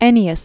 (ĕnē-əs), Quintus 239-169 BC.